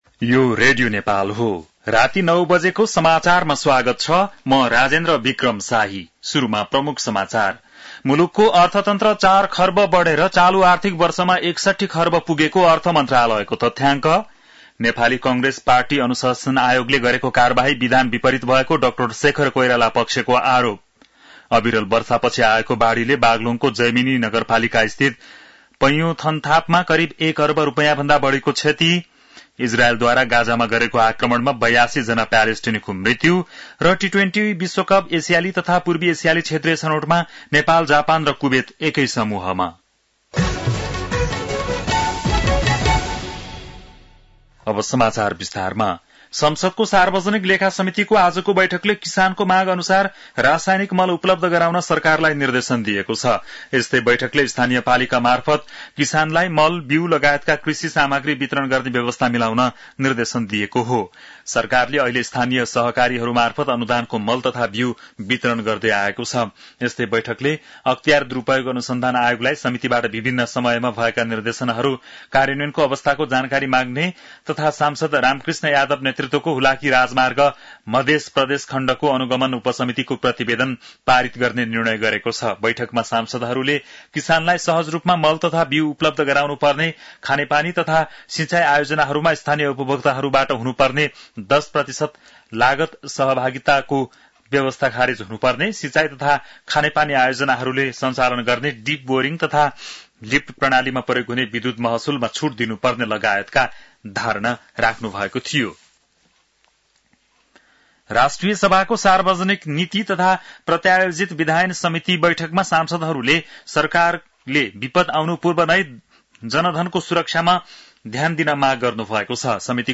बेलुकी ९ बजेको नेपाली समाचार : २६ असार , २०८२
9-pm-nepali-news-3-26.mp3